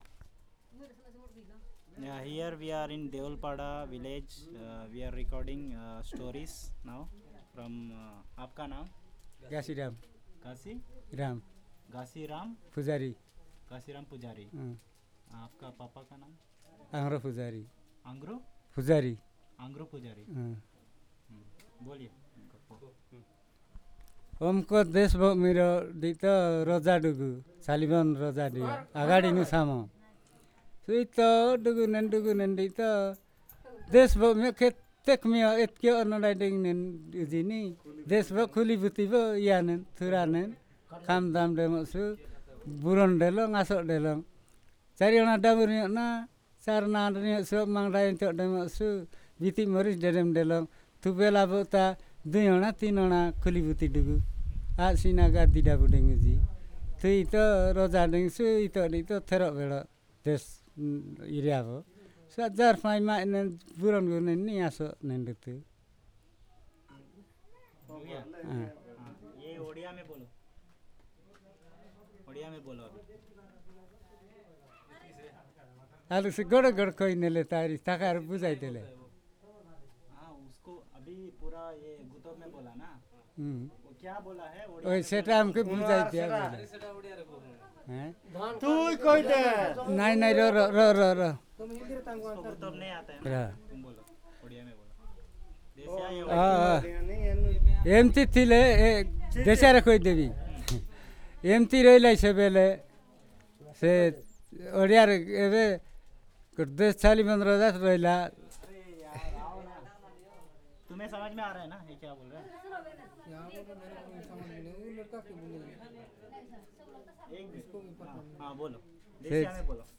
Personal narration on short story